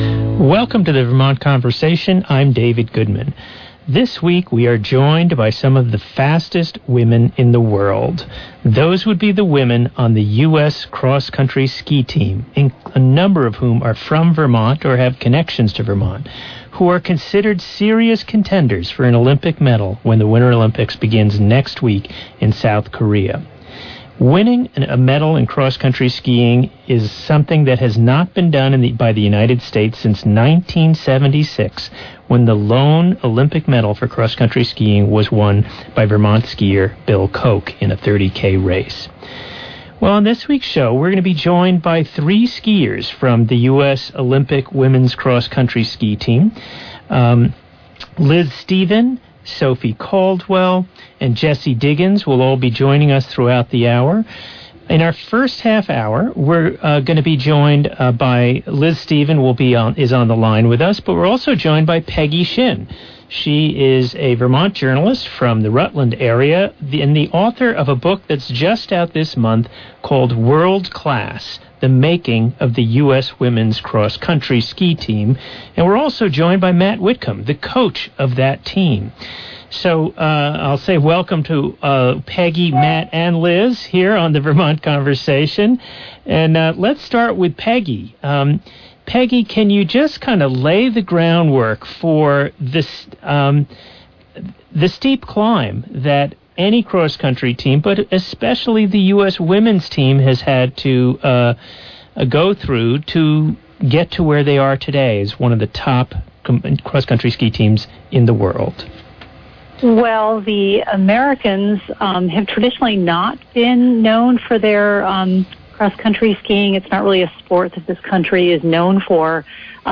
In this Vermont Conversation, and author, coach and skiers discuss the remarkable rise of the US women’s cross-country ski team from being perennial back-of-the-pack finishers to winning numerous medals in world championship races.